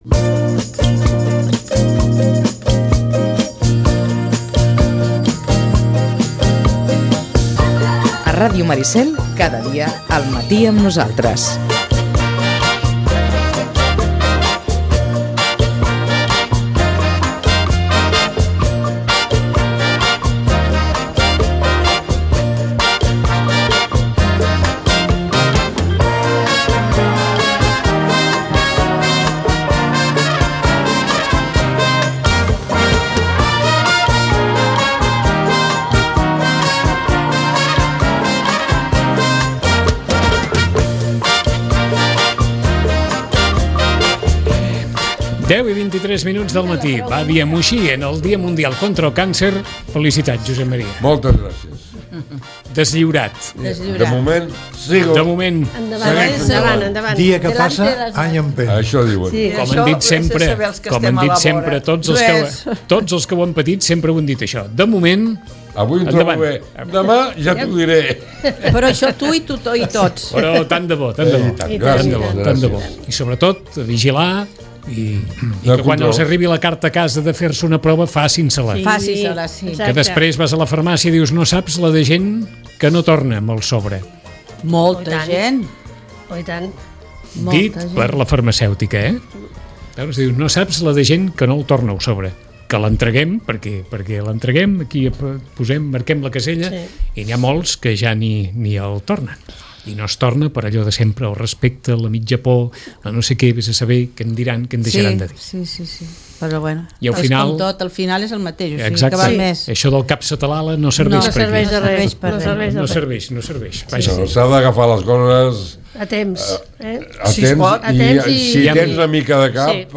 Tertúlia